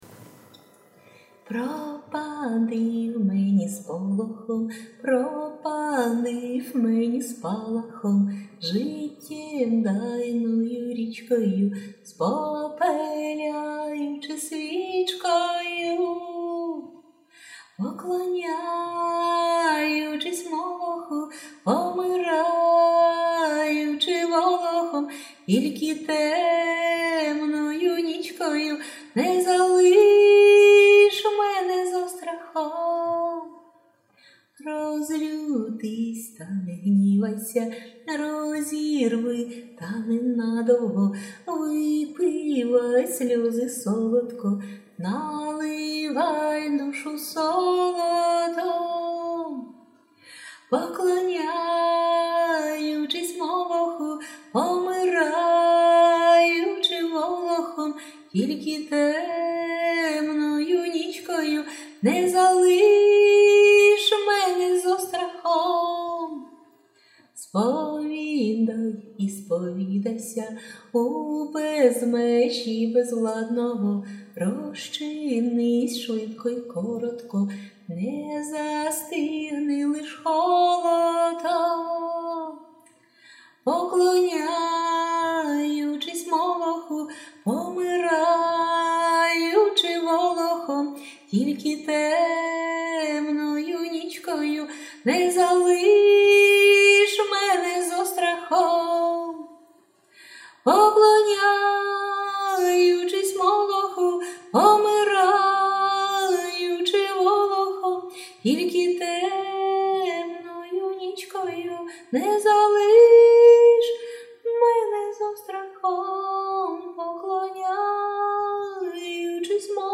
Який приємний тембр голосу!!!